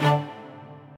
stringsfx2_9.ogg